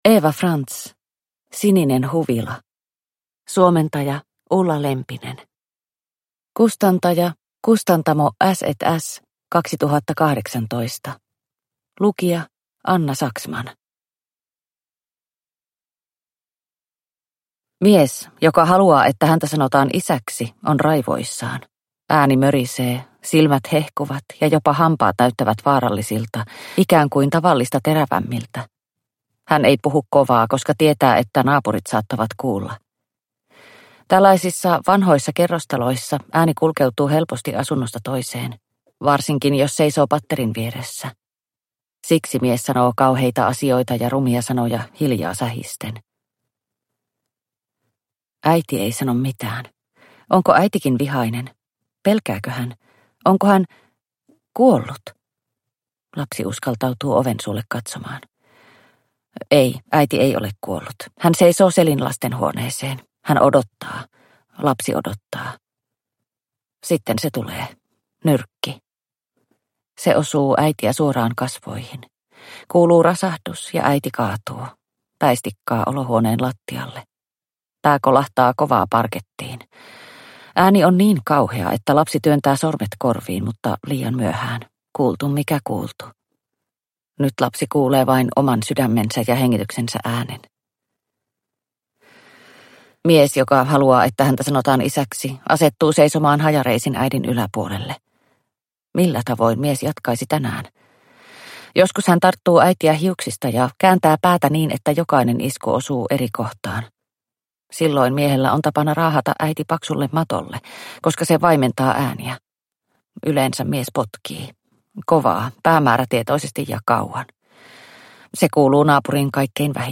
Sininen huvila – Ljudbok – Laddas ner